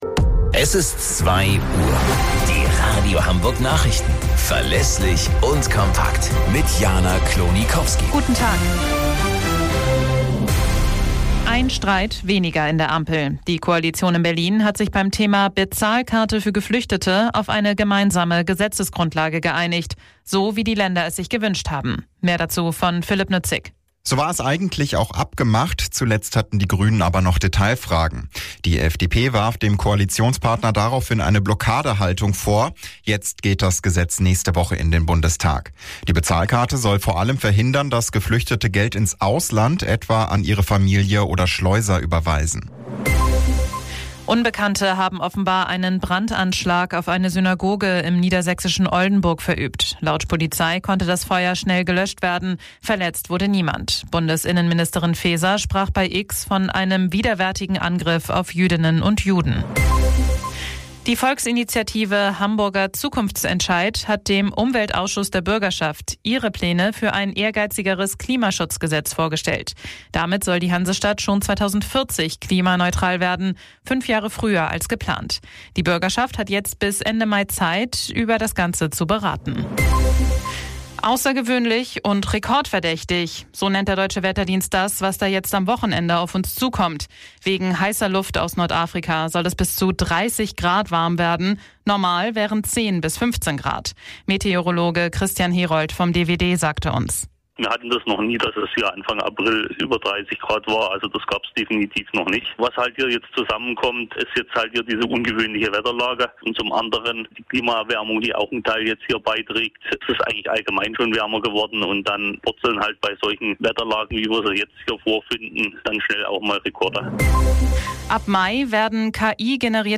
Radio Hamburg Nachrichten vom 06.04.2024 um 09 Uhr - 06.04.2024